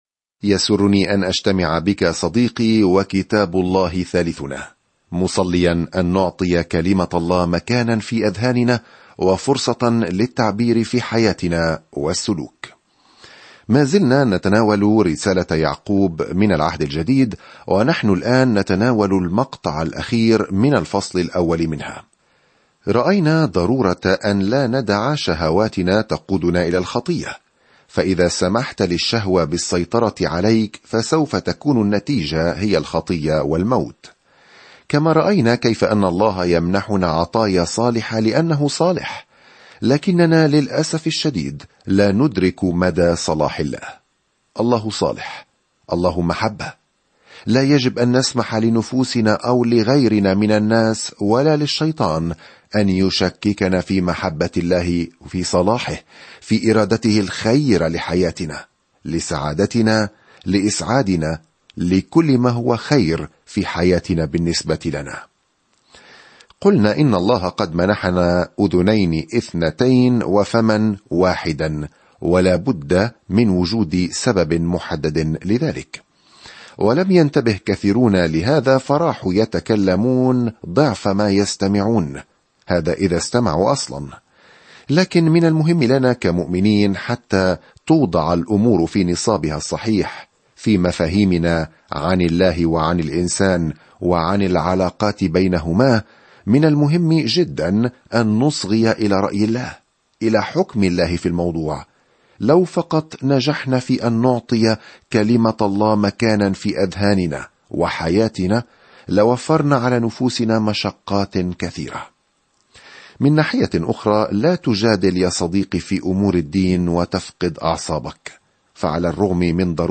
الكلمة يَعْقُوبَ 16:1-19 يوم 5 ابدأ هذه الخطة يوم 7 عن هذه الخطة إذا كنت مؤمنًا بيسوع المسيح، فيجب أن تعكس أفعالك حياتك الجديدة؛ ضع إيمانك موضع التنفيذ. سافر يوميًا عبر جيمس وأنت تستمع إلى الدراسة الصوتية وتقرأ آيات مختارة من كلمة الله.